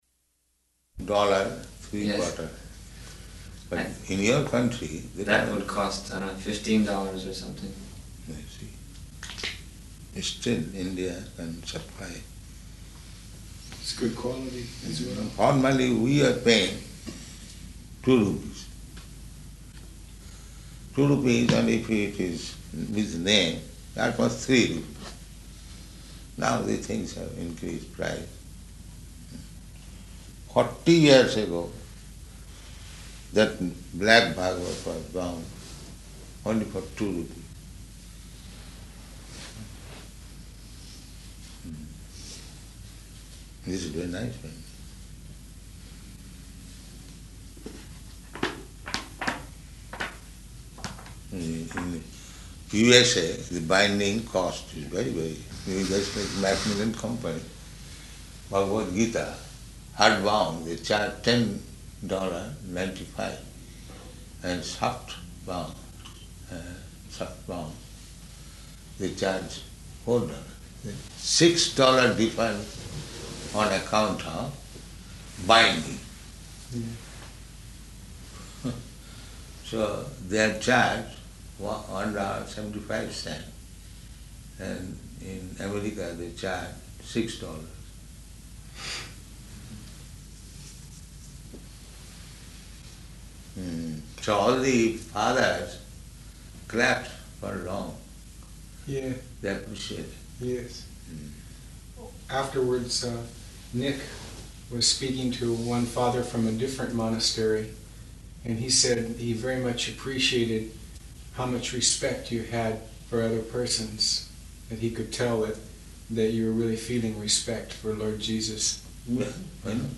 Room Conversation
Room Conversation --:-- --:-- Type: Conversation Dated: June 29th 1974 Location: Melbourne Audio file: 740629R1.MEL.mp3 Prabhupāda: ...dollar, three quarter.